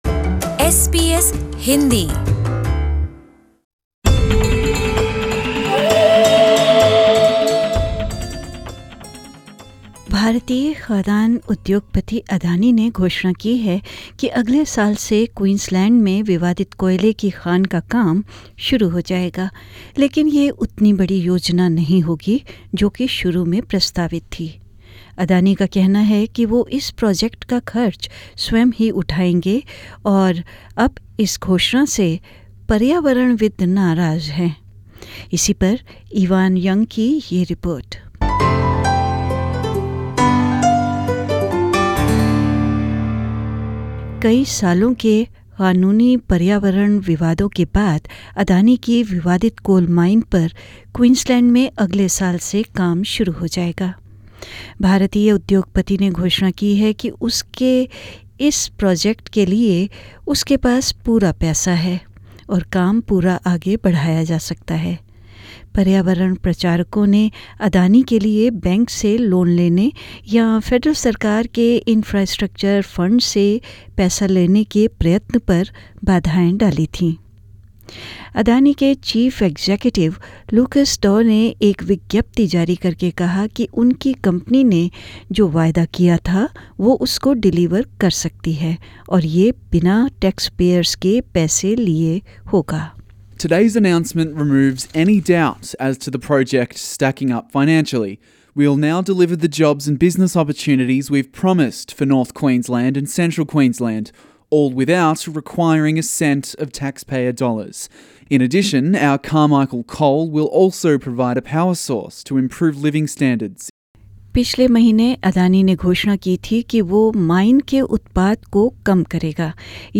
भारतीय खादान उद्योगपति अदानी ने घोषणा की है कि अगले साल से Queensland में विवादित कोयले की खान का काम शुरू हो जायेगा लेकिन यह उतनी बड़ी योजना नहीं होगी जो शुरू में प्रस्तावित थी। इसी पर एक रिपोर्ट ...